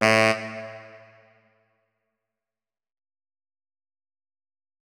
3098b9f051 Divergent / mods / Hideout Furniture / gamedata / sounds / interface / keyboard / saxophone / notes-21.ogg 53 KiB (Stored with Git LFS) Raw History